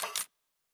pgs/Assets/Audio/Sci-Fi Sounds/Weapons/Weapon 14 Foley 3 (Flamethrower).wav at master
Weapon 14 Foley 3 (Flamethrower).wav